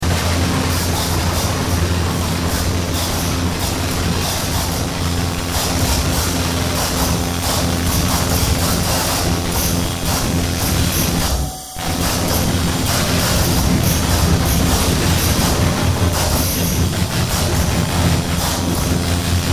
Noise Performance available media: mp3